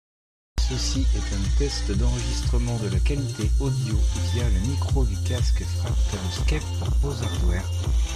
Trong môi trường ồn ào (mức nhạc giống như khi kiểm tra micro rời), giọng nói không may bị lẫn vào nhạc, nhưng vẫn có thể nghe được với chút khó khăn.
• [Mẫu âm thanh Micro tích hợp đa hướng và nhạc]